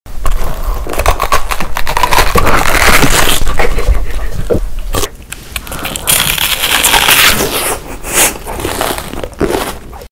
Random color food mukbang Korean sound effects free download
ASMR Testing mukbang Eating Sounds